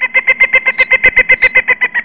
JungleBird.mp3